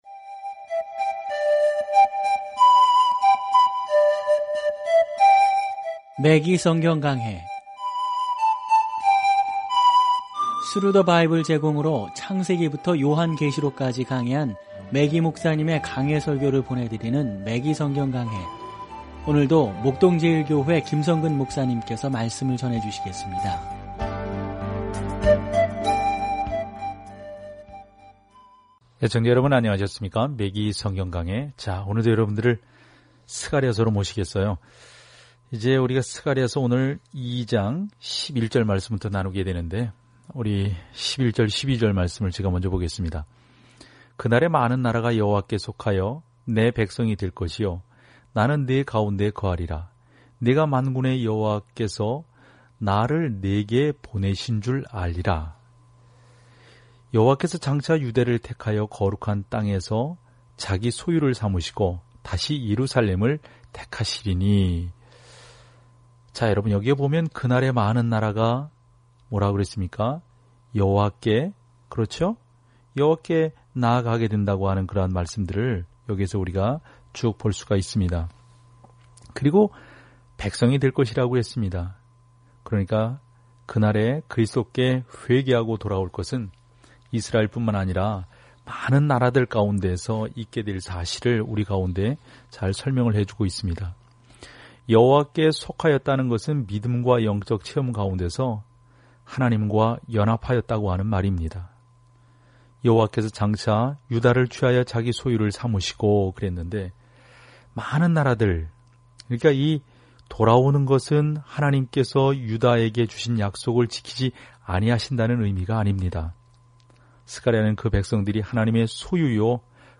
말씀 스가랴 2:11-13 스가랴 3:1-2 5 묵상 계획 시작 7 묵상 소개 선지자 스가랴는 사람들에게 미래의 희망을 주겠다는 하나님의 약속에 대한 환상을 공유하고 그들에게 하나님께 돌아올 것을 촉구합니다. 오디오 공부를 듣고 하나님의 말씀에서 선택한 구절을 읽으면서 매일 스가랴를 여행하세요.